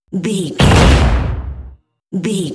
Cinematic drum hit trailer
Sound Effects
Atonal
heavy
intense
dark
aggressive